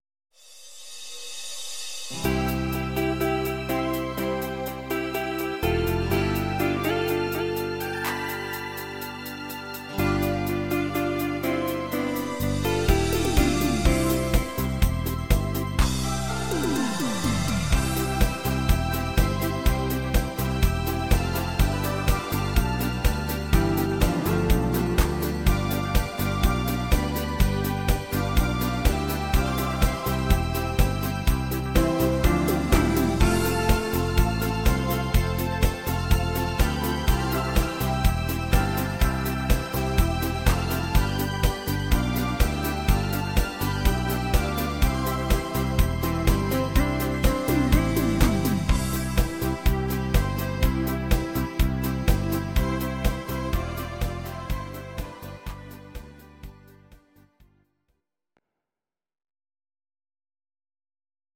Please note: no vocals and no karaoke included.
(Version für die Sängerin)